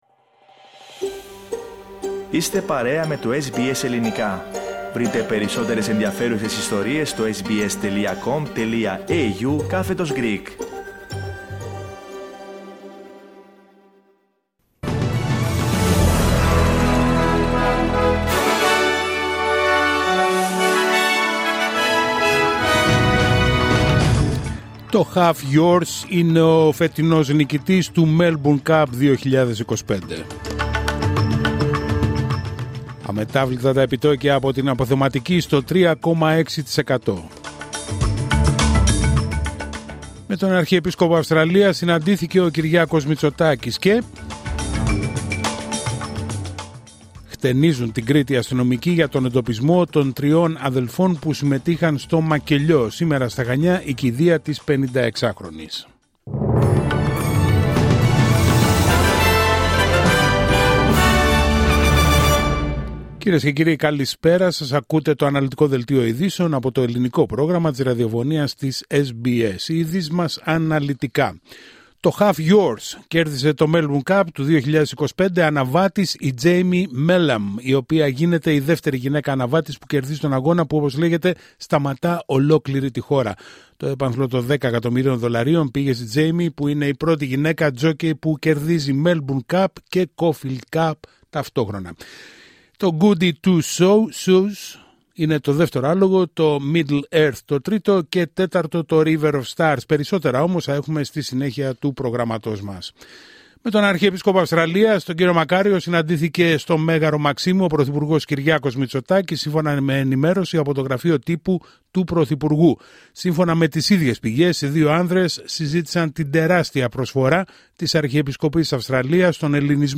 Δελτίο ειδήσεων Τρίτη 4 Νοεμβρίου 2025